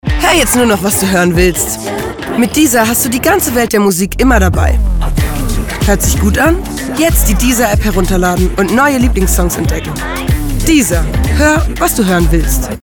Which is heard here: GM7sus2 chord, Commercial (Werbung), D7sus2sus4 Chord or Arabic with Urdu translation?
Commercial (Werbung)